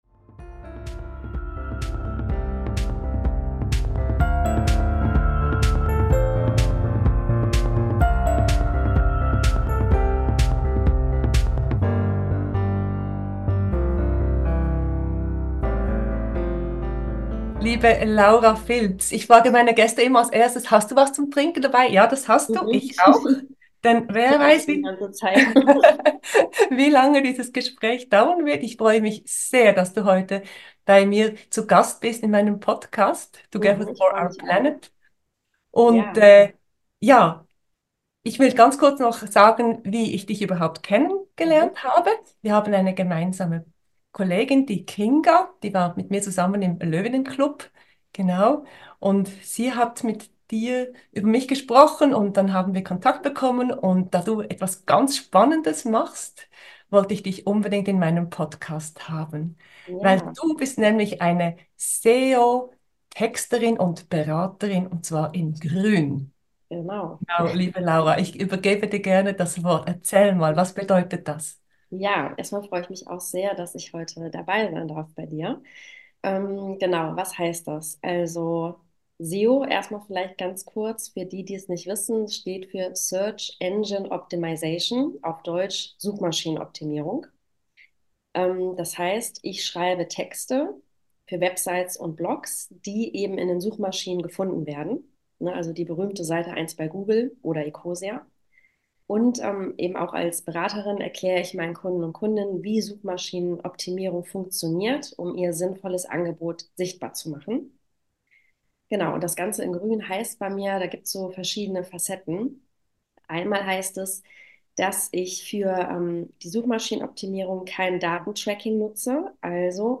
Im Gespräch mit mir erzählt sie von ihren Erfahrungen und gibt praktische Tipps, wie auch Du Deine Webseite nachhaltiger und sichtbarer gestalten kannst.